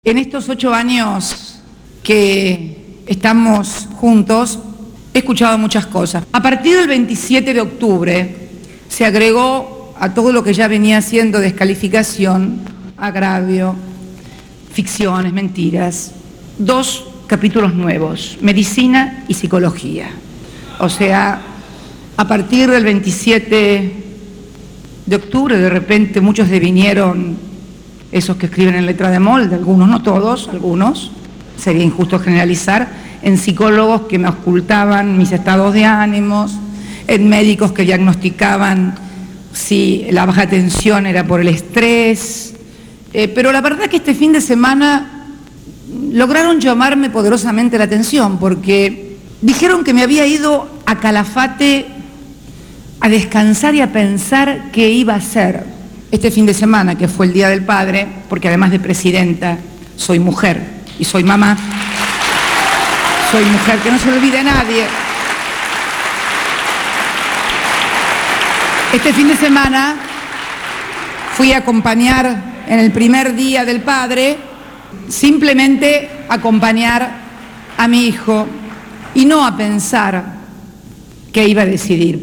En la Casa de Gobierno, en el marco de la inauguración de la TV Digital para Jujuy y Entre Ríos, la Presidenta anunció su candidatura presidencial.